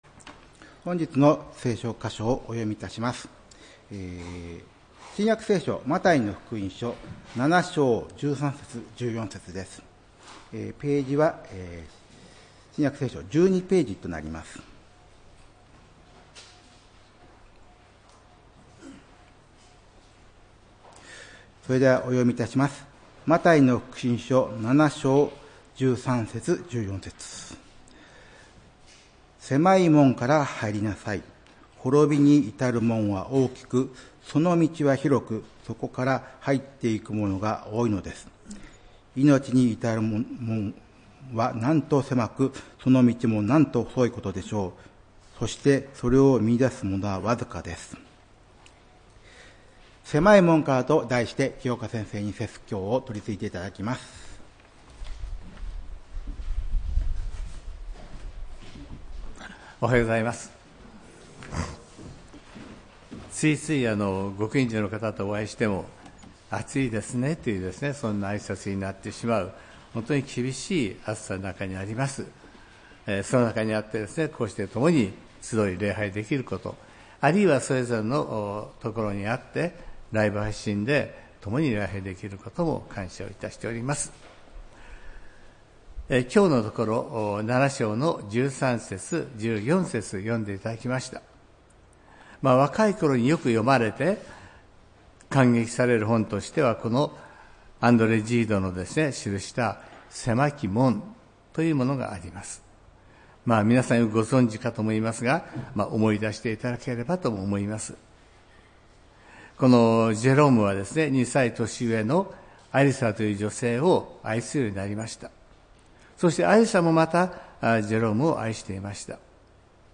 礼拝メッセージ「狭い門から」(７月27日）